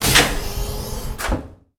tbd-station-14/Resources/Audio/Machines/airlock_open.ogg
airlock_open.ogg